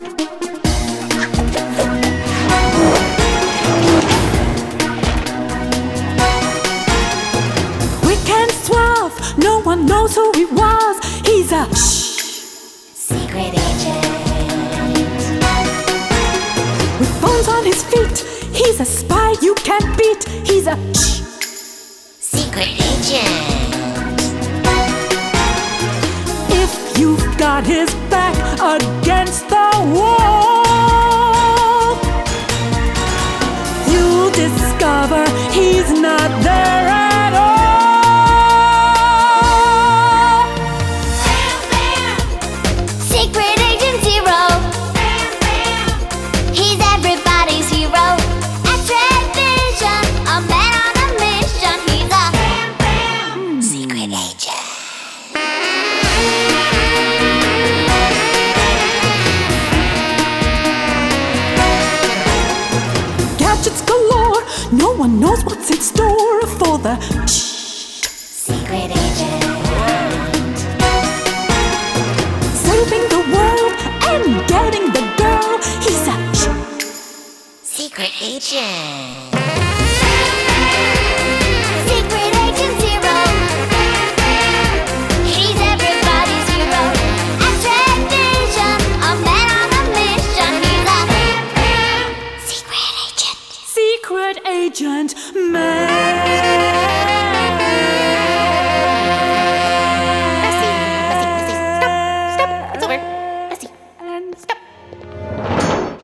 BPM130-135
Audio QualityCut From Video